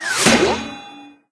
safe_drop_01.ogg